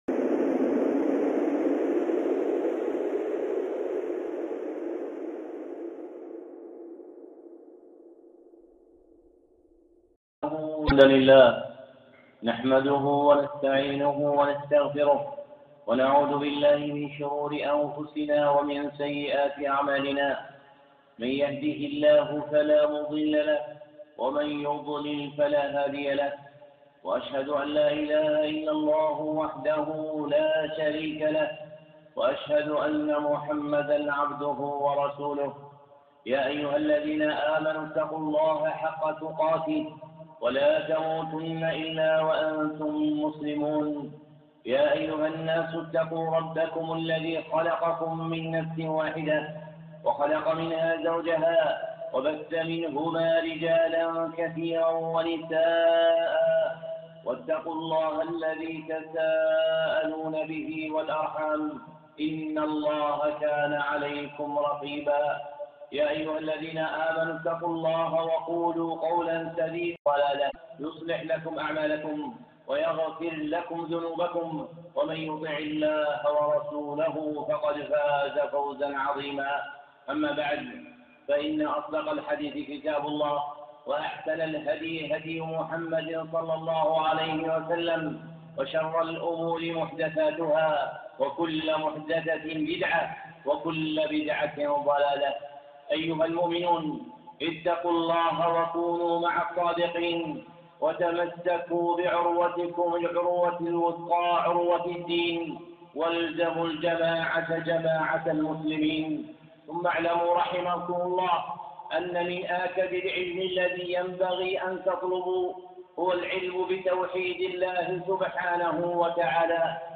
خطبة (أهمية علم التوحيد)